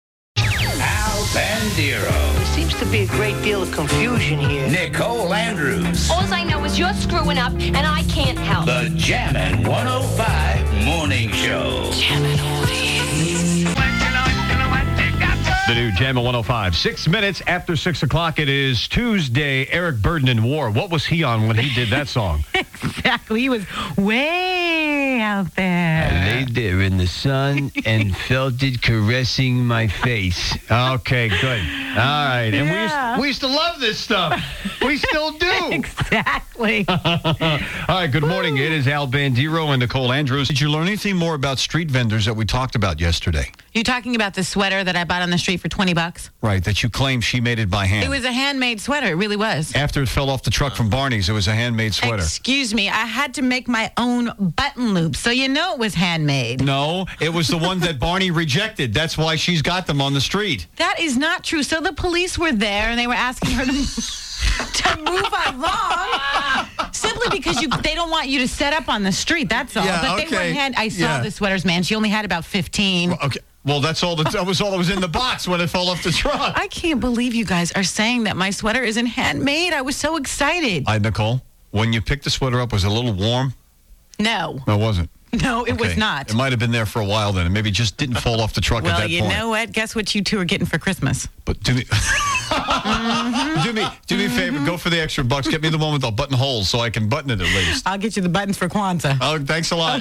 On-Air Personality
Morning Show Clip